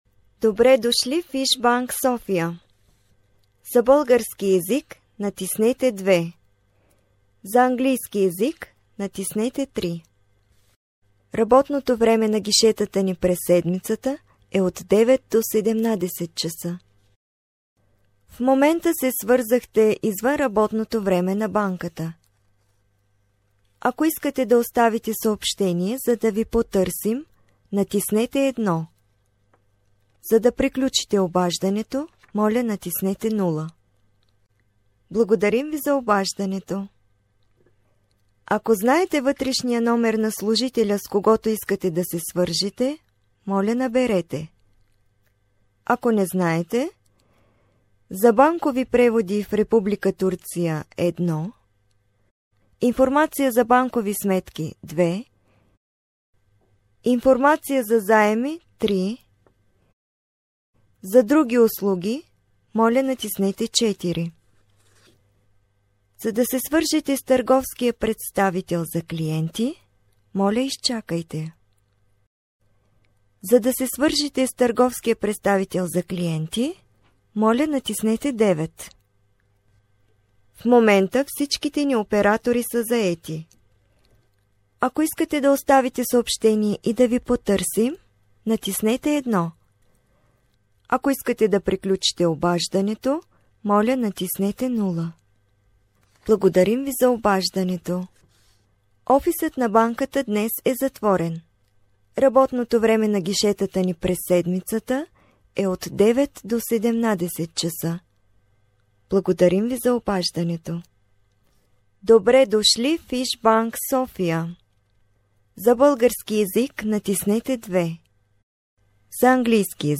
Yabancı Seslendirme Kadrosu
Yabancı Seslendirme Kadrosu, yabancı sesler